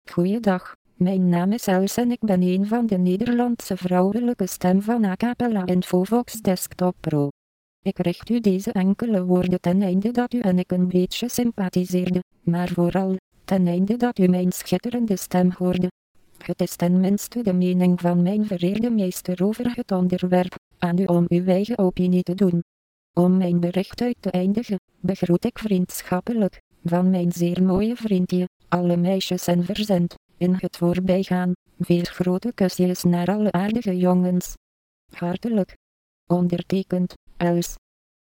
Texte de démonstration lu par Els, voix féminine néerlandaise d'Acapela Infovox Desktop Pro
Écouter la démonstration d'Els, voix féminine néerlandaise d'Acapela Infovox Desktop Pro